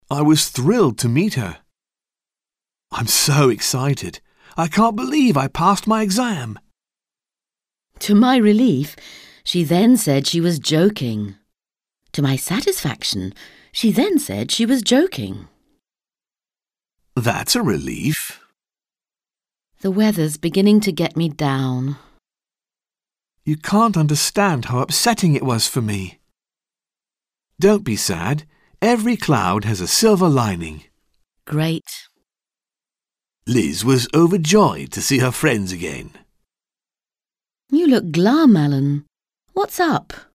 Un peu de conversation - Joie, soulagement et tristesse